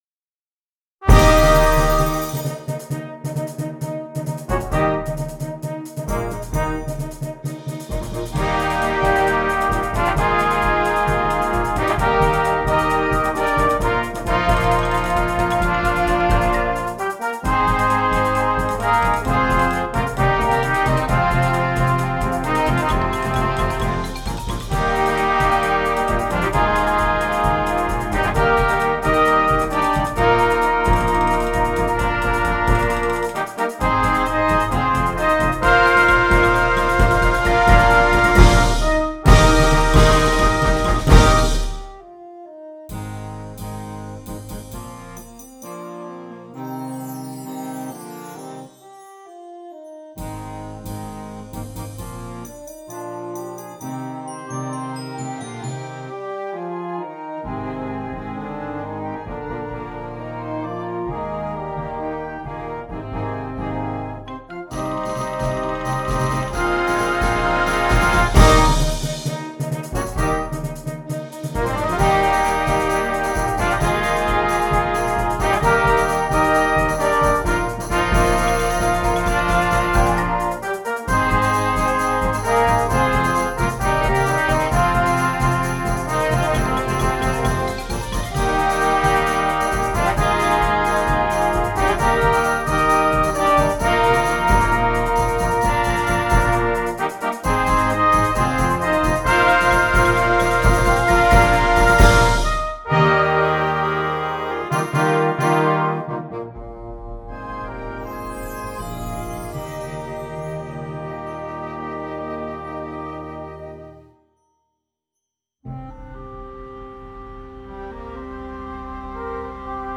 Concert Band
an overture